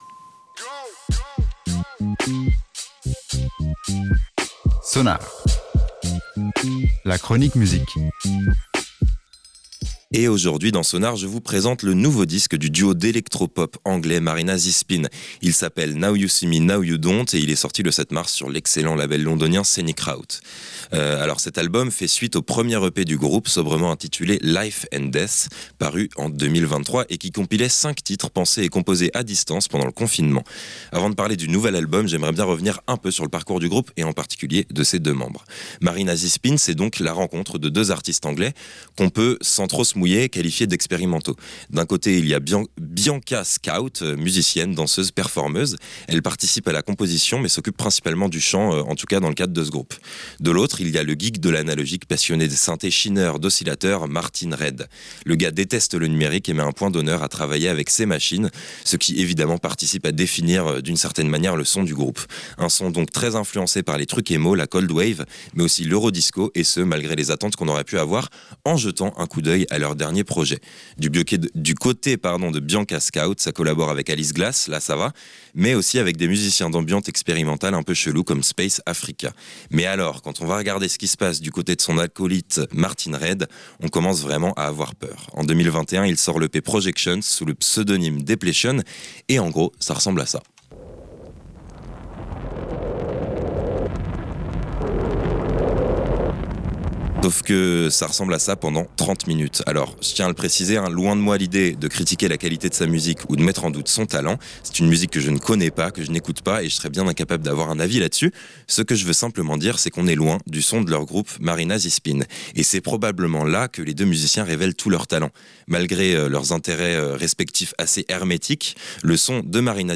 duo électronique anglais
synthés des années 1980 à 2000